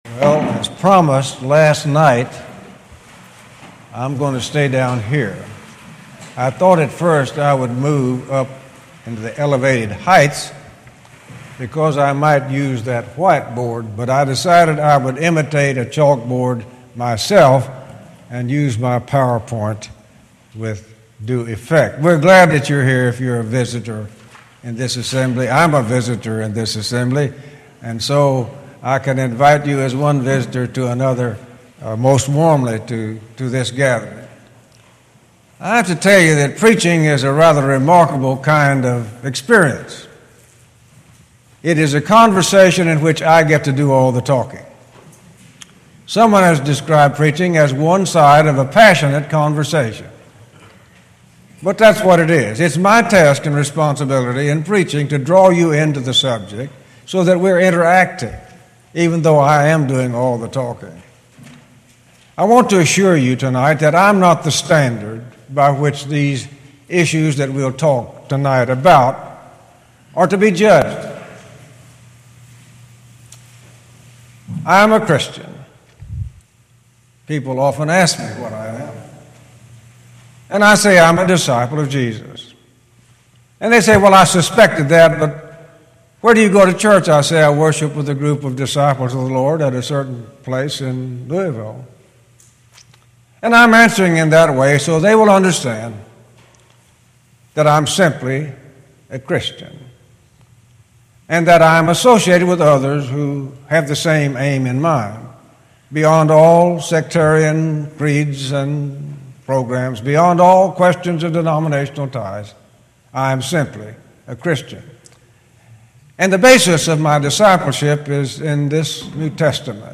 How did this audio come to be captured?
Series: Gettysburg 2013 Gospel Meeting